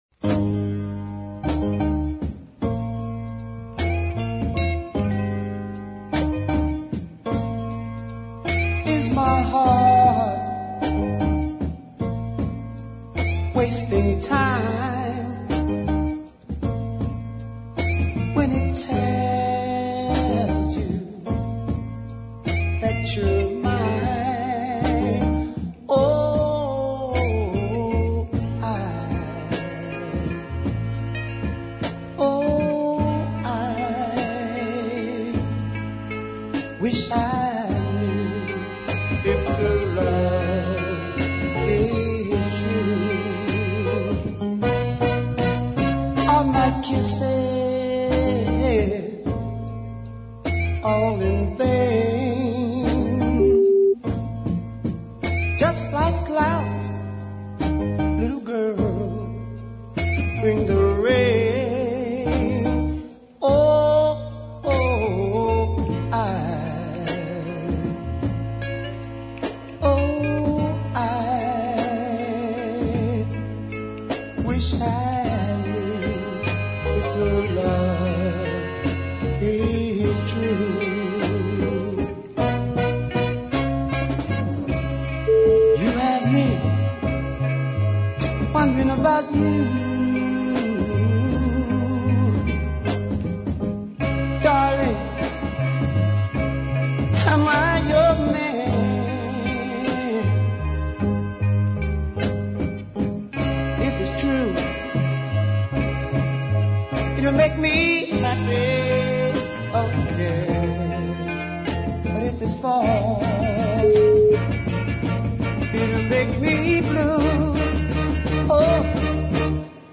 A lovely ballad beautifully realised.